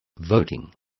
Complete with pronunciation of the translation of voting.